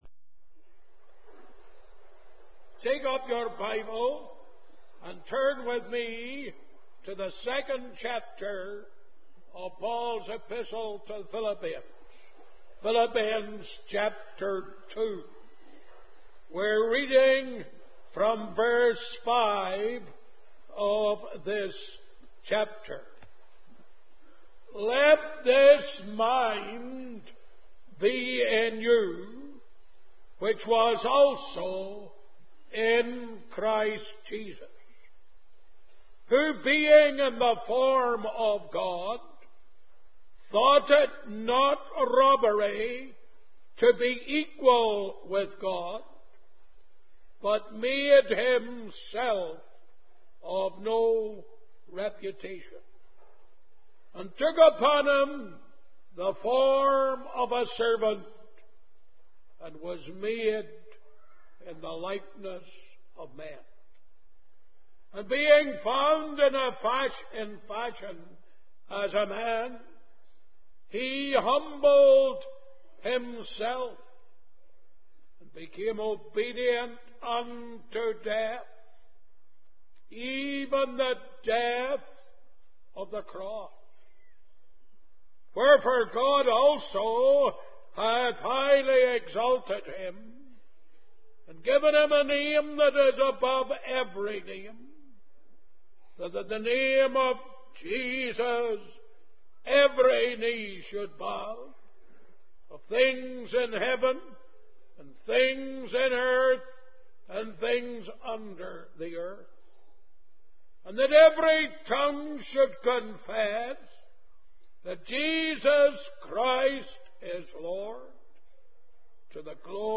In this sermon, the preacher emphasizes the urgency of making a decision about one's eternal destiny.